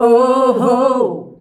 OOOHOO  C.wav